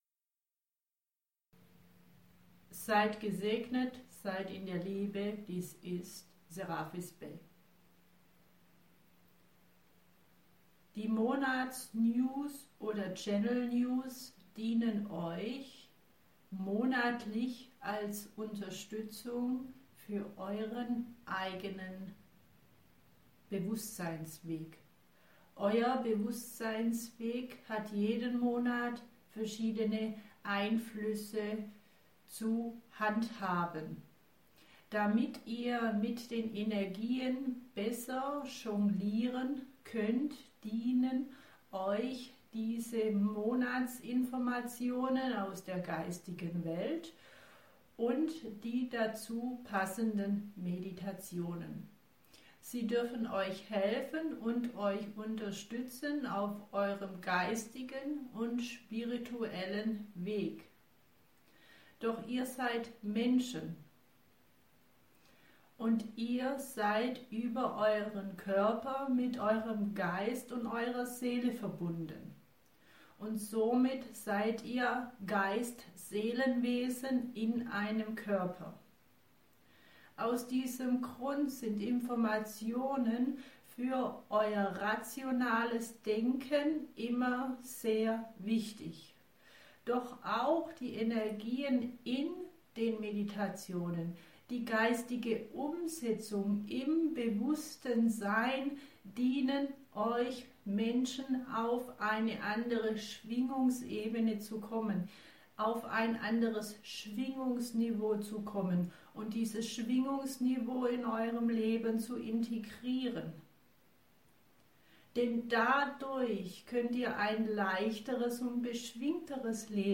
und die die Erklärung von mir zu den Monatsinfos auf Schwäbisch.